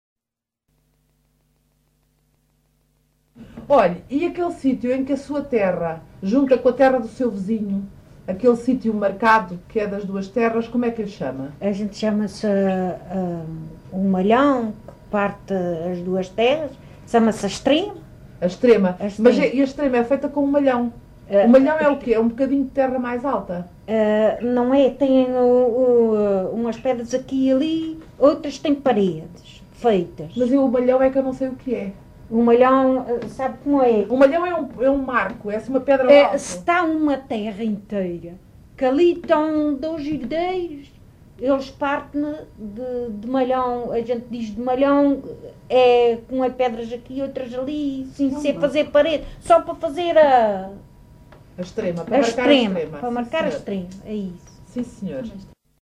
LocalidadeFajãzinha (Lajes das Flores, Horta)